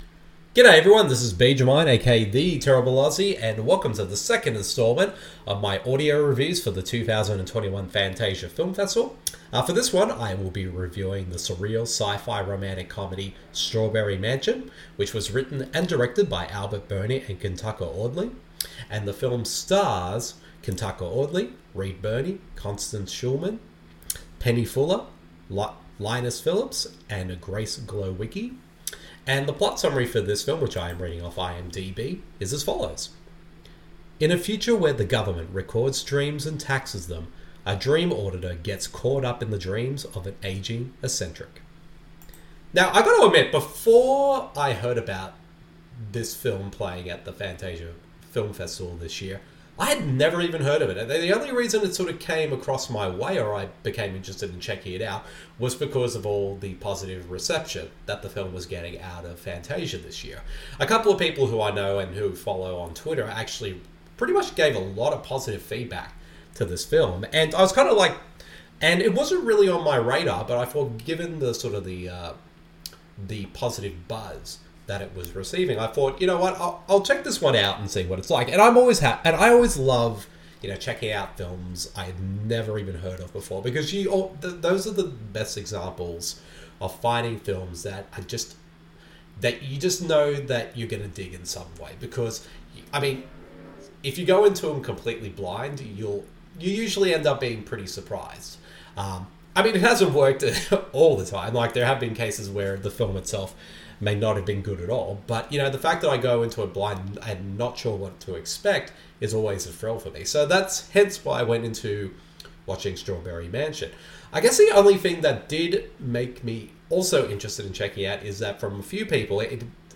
The following review of the film is in an audio format.